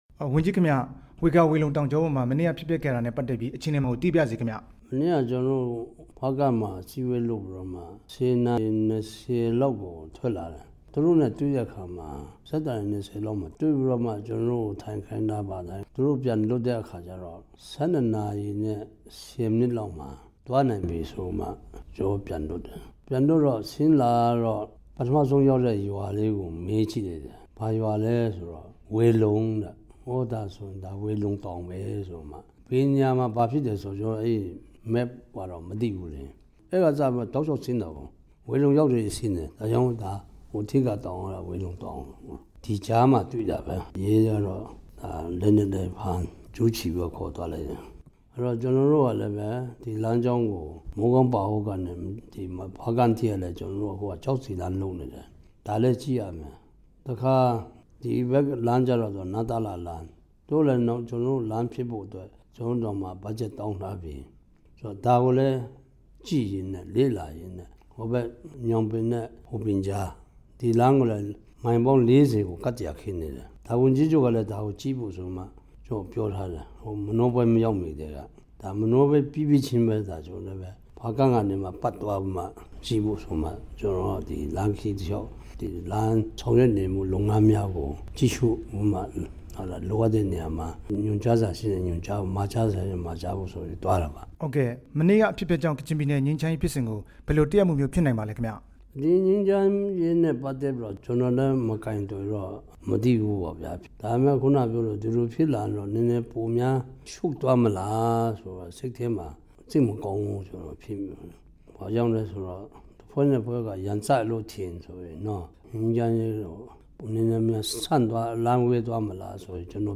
လွတ်မြောက်လာသူ ကချင်ပြည်နယ် အစိုးရဝန်ကြီးနဲ့ မေးမြန်းချက်